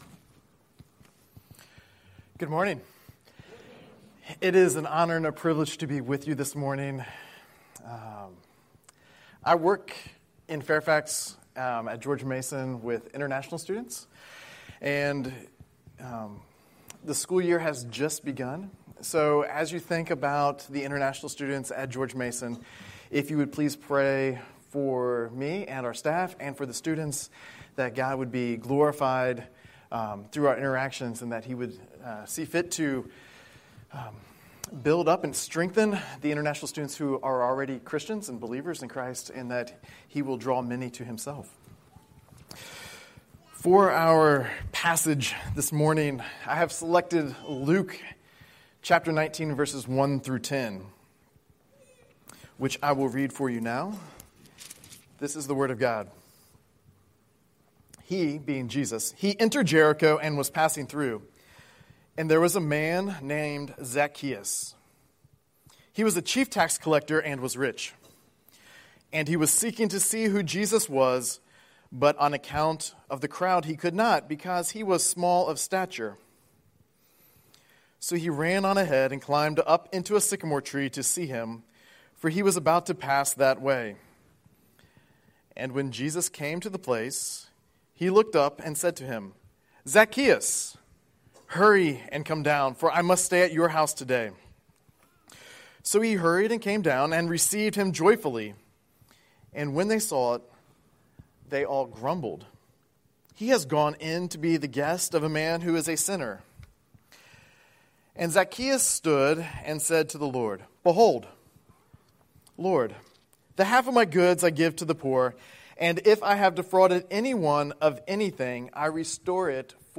Scripture: Luke 19:1-10 Series: Sunday Worship